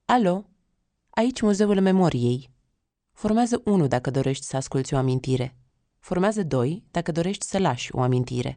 Așa începe înregistrarea de pe Telefonul Amintirilor, o instalație interactivă cu două funcții: de redare și înregistrare.